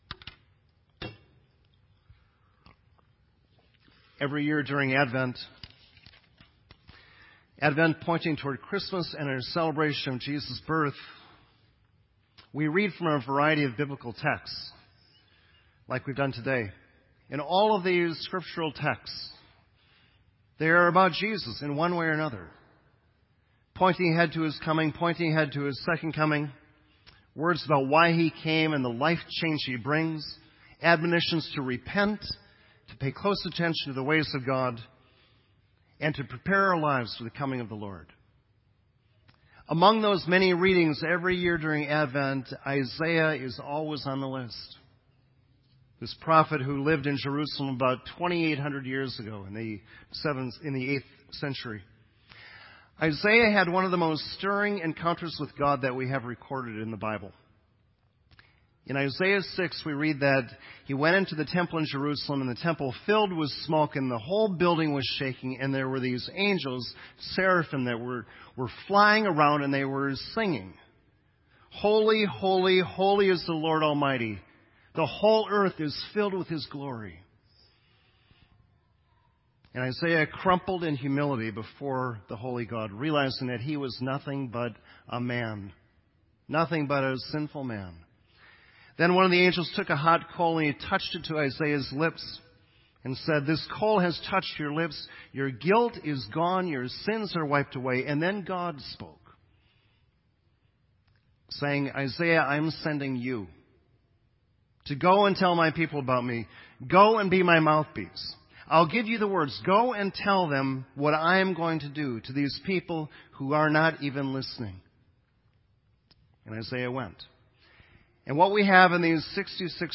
Worship Service
Sermon Audio